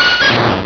Cri de Kapoera dans Pokémon Rubis et Saphir.
Cri_0237_RS.ogg